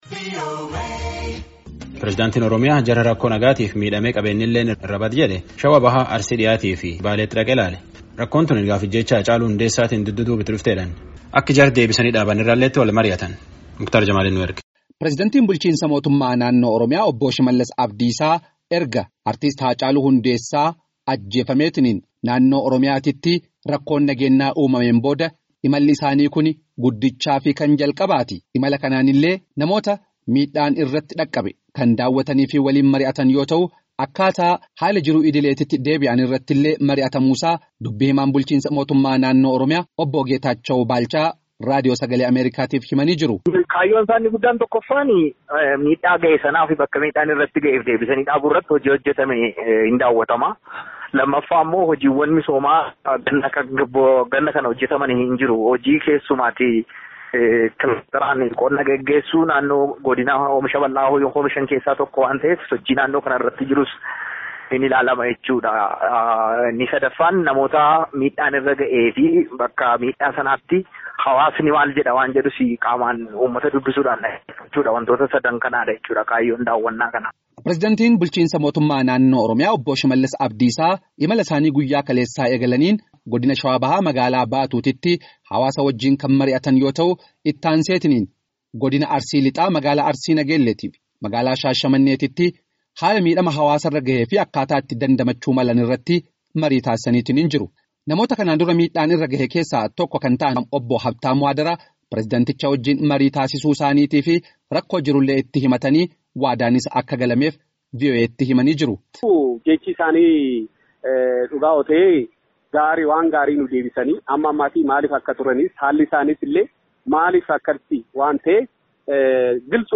Gabaasaa guutu caqasaa.